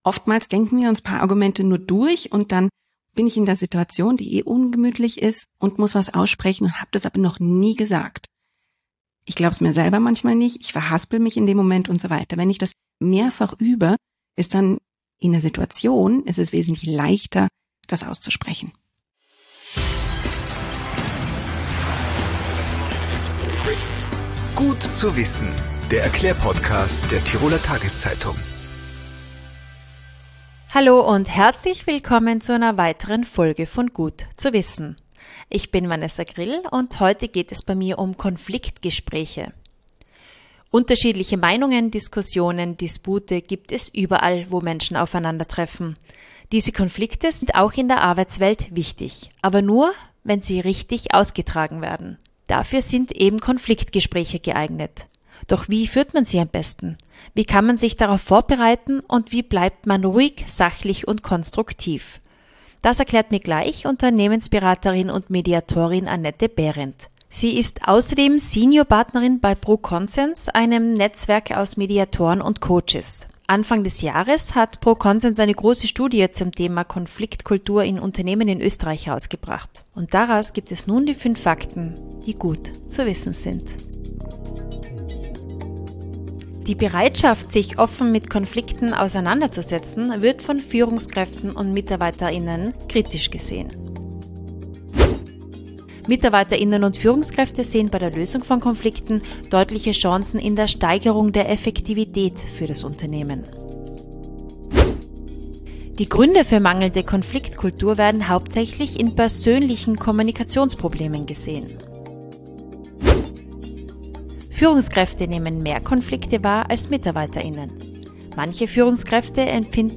Tipps, Erklärungen und Erfahrungen: Wöchentlich gibt es im "Gut zu wissen"-Podcast der Tiroler Tageszeitung interessante und unterhaltsame Gespräche mit Experten oder Betroffenen rund um die Themen Gesundheit, Lifestyle, Ernährung, Gesellschaft, Freizeit & Beruf, Familie & Beziehungen und sonstige wichtige Alltagsthemen.